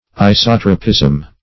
Isotropism \I*sot"ro*pism\, n.